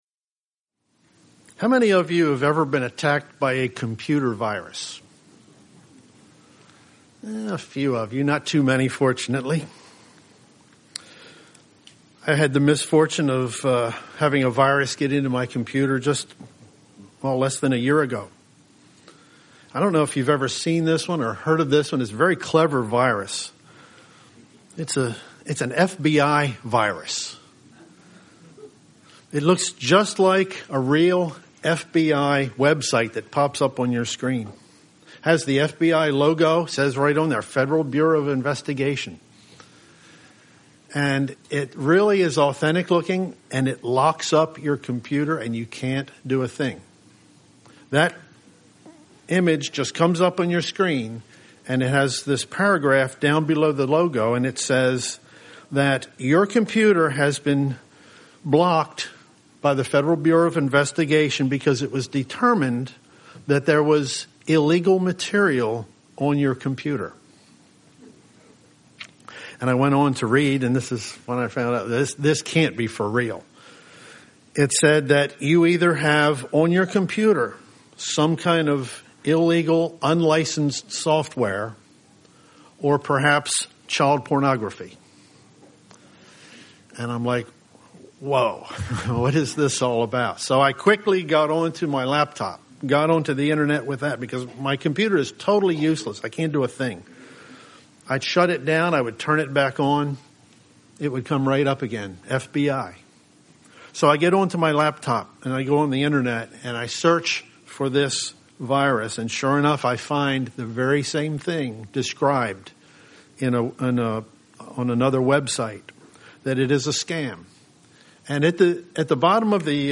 This sermon was given at the Ocean City, Maryland 2013 Feast site.